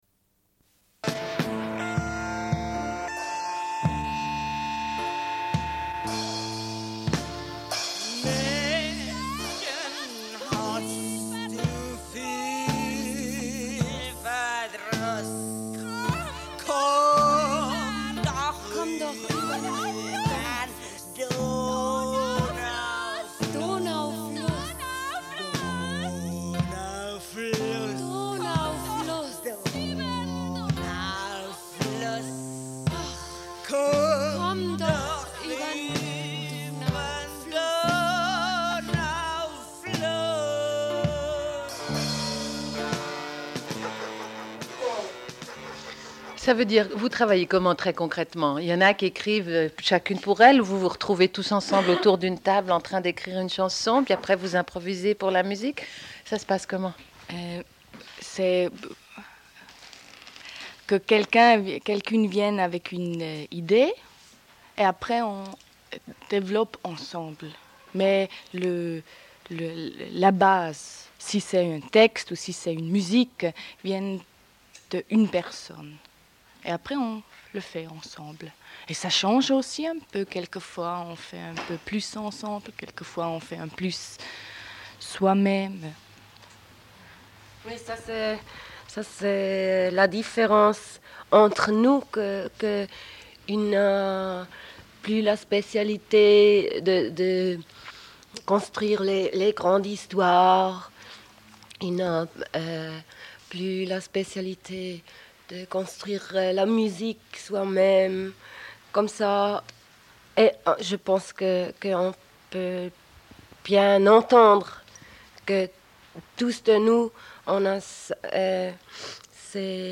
Deuxième partie de l'émission, diffusion d'un entretien au sujet des Reine Prochaines, suite à un concert à la Cave 12.